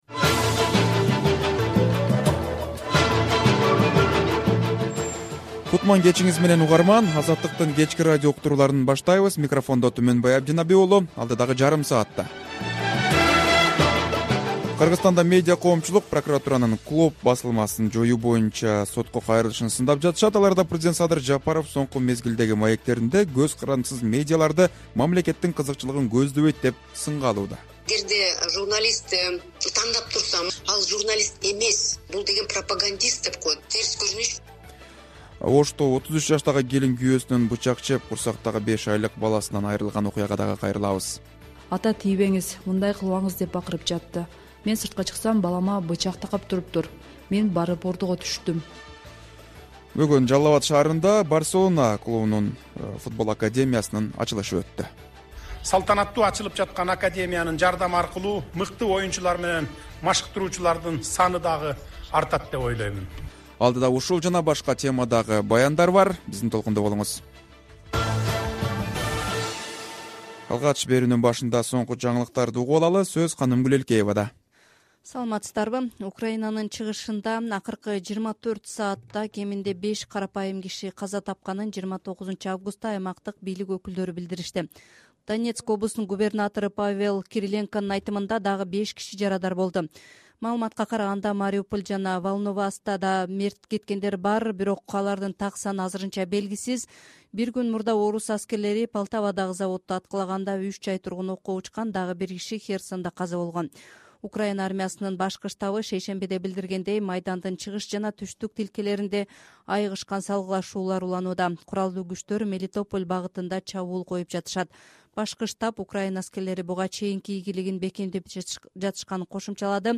Кечки радио эфир | 29.08.2023 | Жалал-Абадда "Барселонанын" футбол академиясы ачылды